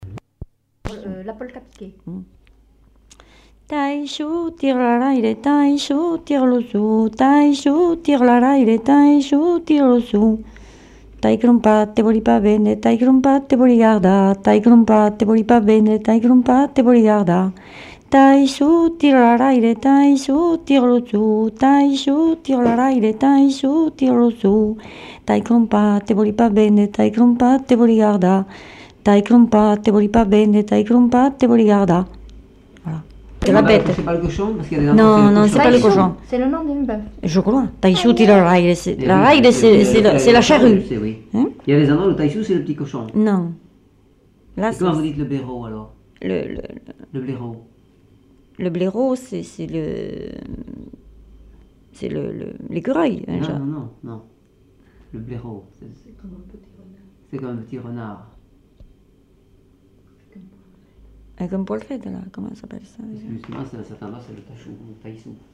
Lieu : Lodève
Genre : chant
Effectif : 1
Type de voix : voix de femme
Production du son : chanté
Danse : polka piquée